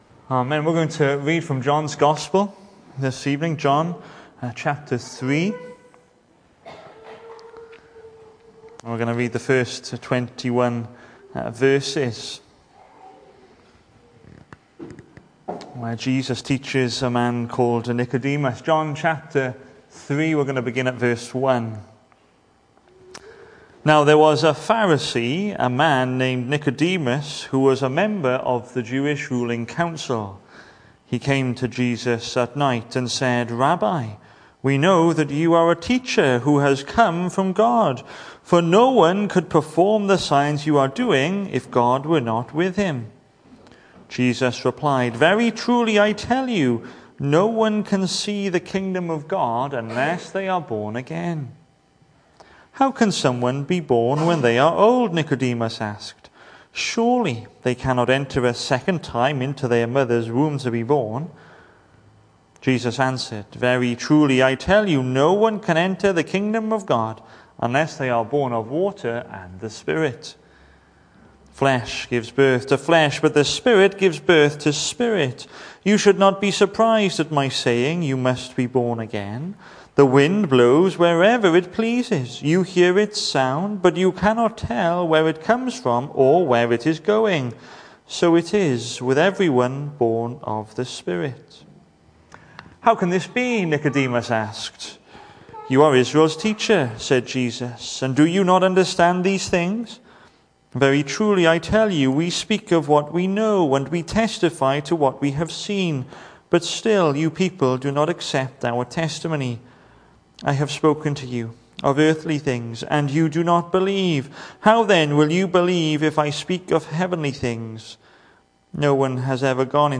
The 24th of August saw us hold our evening service from the building, with a livestream available via Facebook.